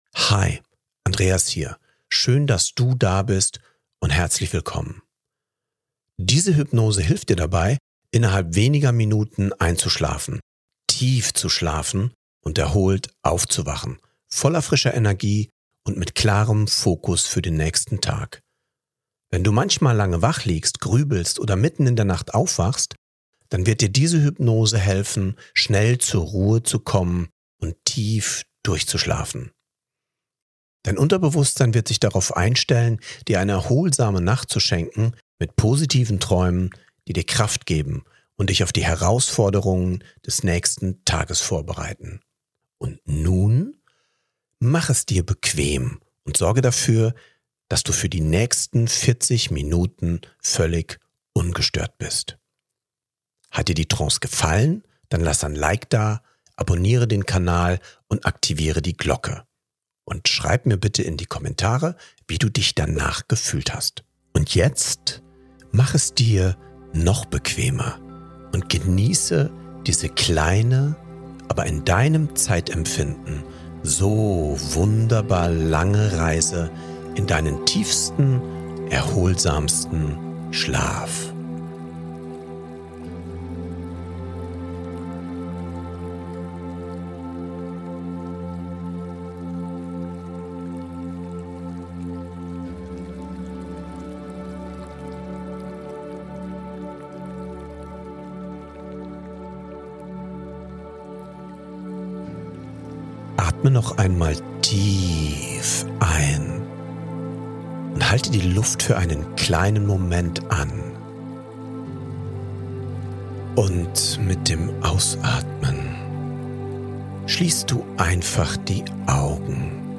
TIEFSCHLAF HYPNOSE Schnell einschlafen & erholt aufwachen | Schlaf verbessern mit Hypnose ~ Happiness Mindset Podcast